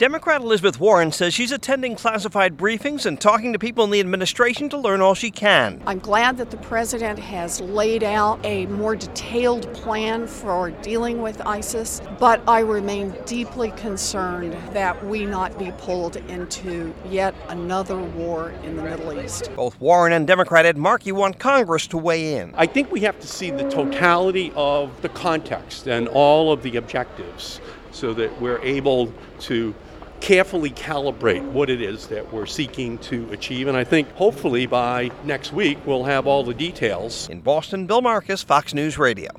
CAUGHT UP WITH BOTH OF THEM IN BOSTON: